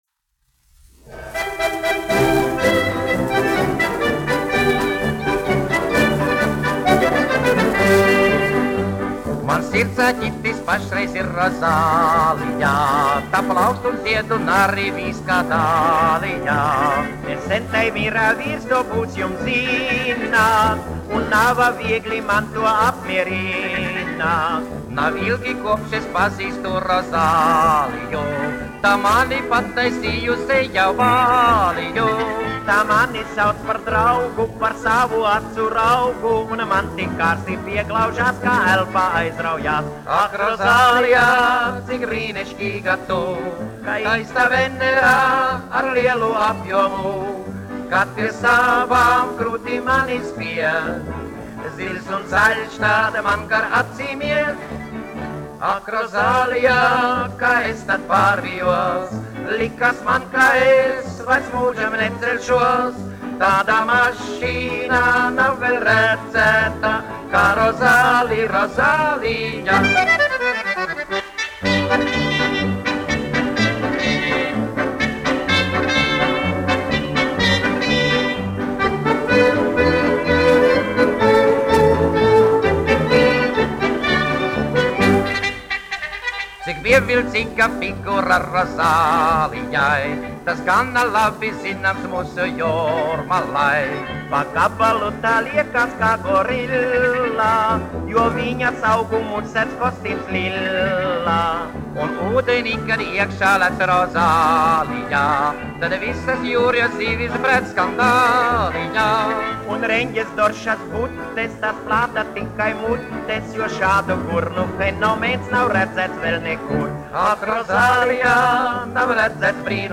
1 skpl. : analogs, 78 apgr/min, mono ; 25 cm
Fokstroti
Populārā mūzika
Latvijas vēsturiskie šellaka skaņuplašu ieraksti (Kolekcija)